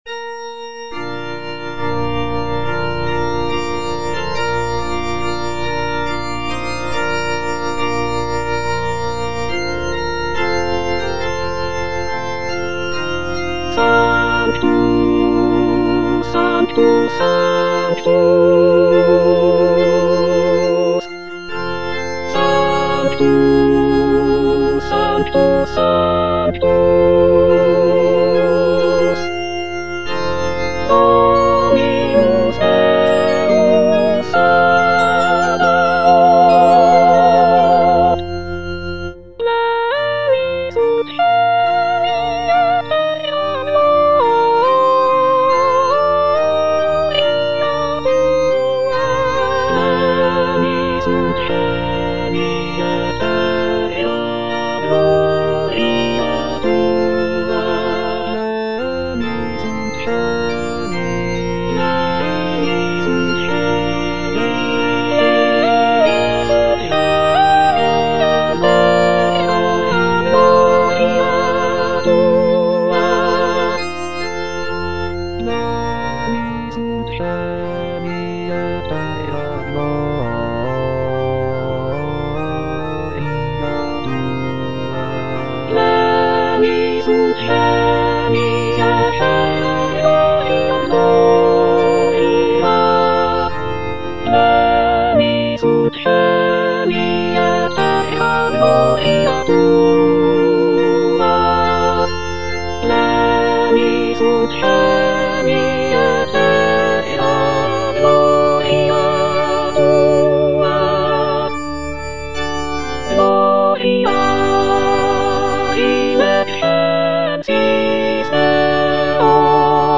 Soprano (Emphasised voice and other voices) Ads stop
is a sacred choral work rooted in his Christian faith.